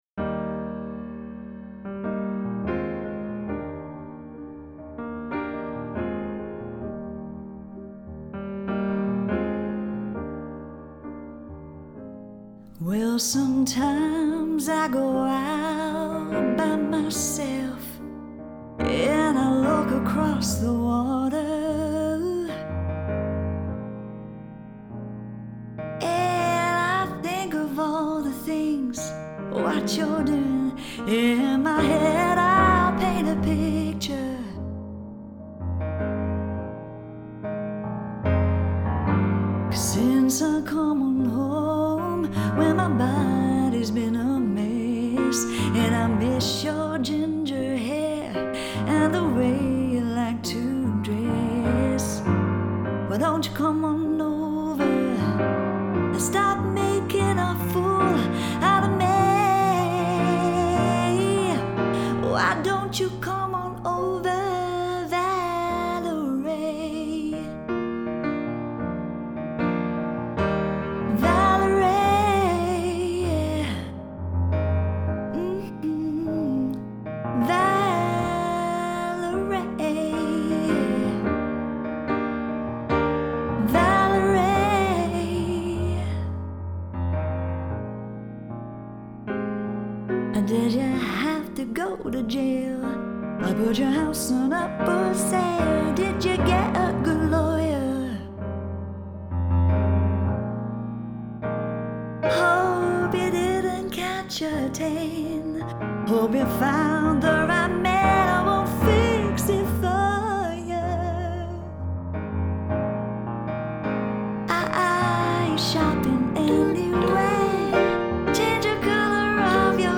etc. All performed with only piano and three vocals.
Quite simply - it sounds beautiful.